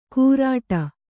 ♪ kūrāṭa